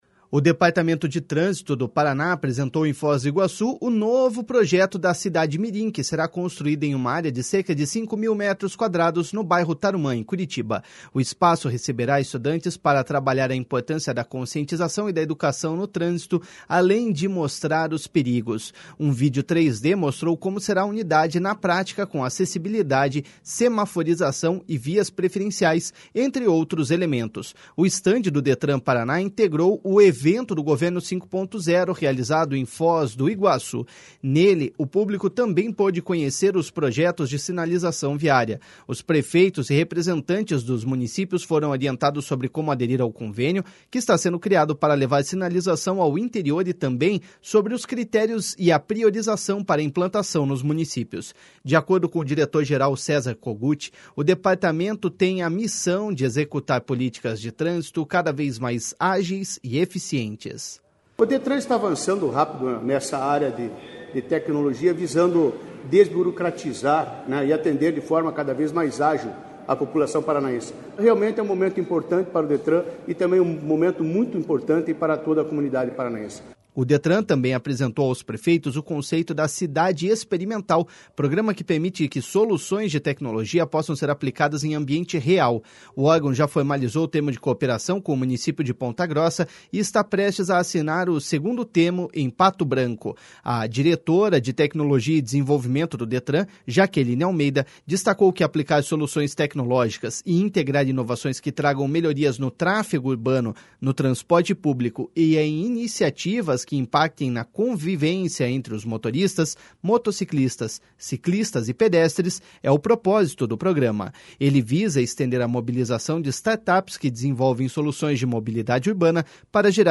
O estande do Detran-PR integrou o evento Governo 5.0, realizado em Foz do Iguaçu.